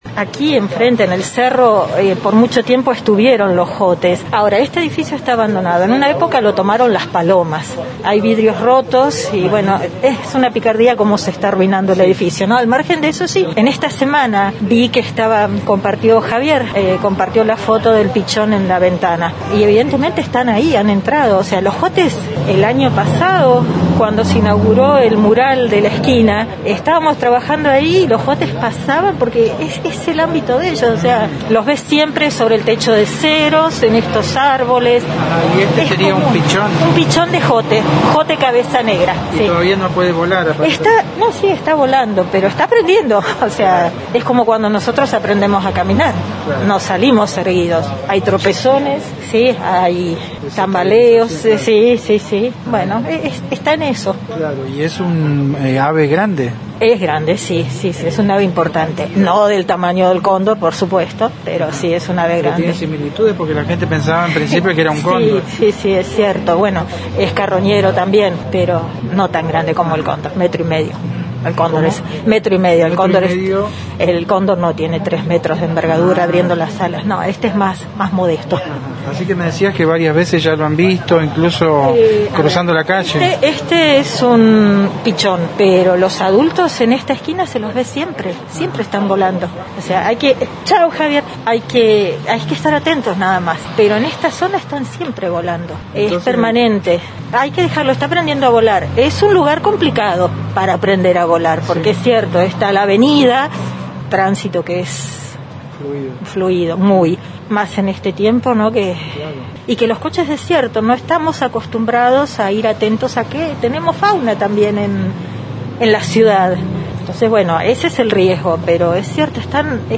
quien dialogó con Noticias de Esquel y relató que se trata de un pichón de Jote que está aprendiendo a volar.